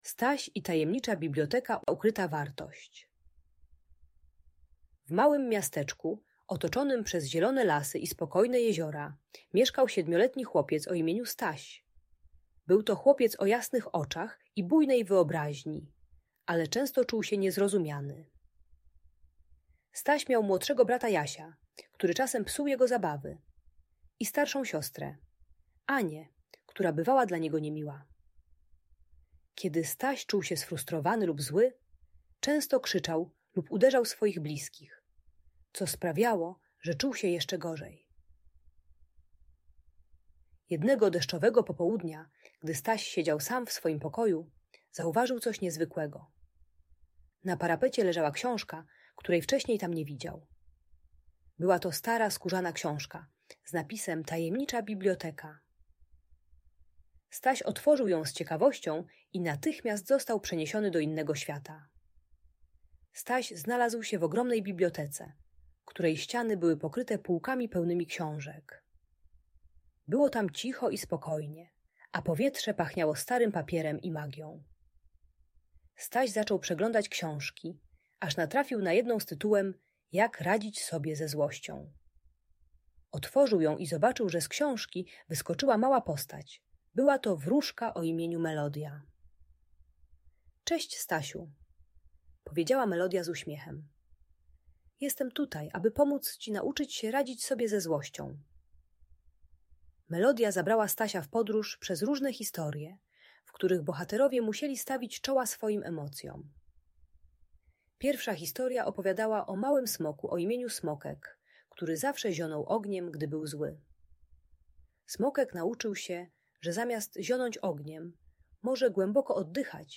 Staś i Tajemnicza Biblioteka - Audiobajka